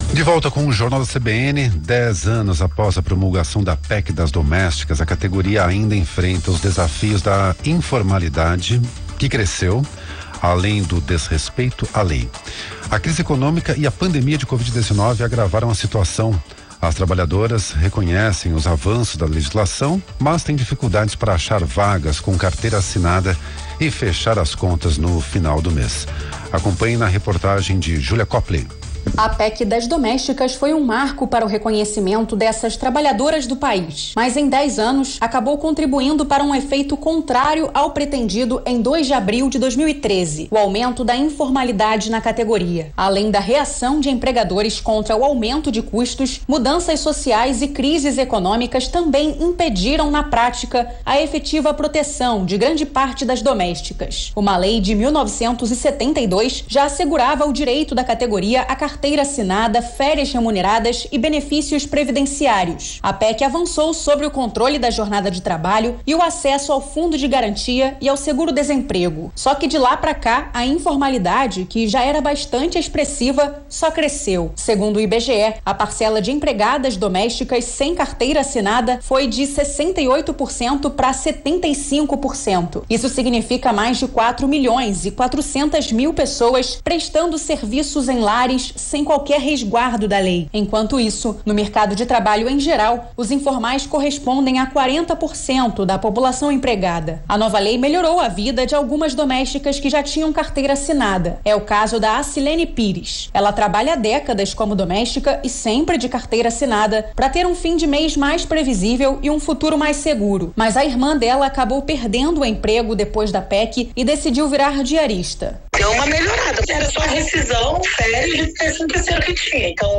Rádio CBN - RJ Mídia: Rádio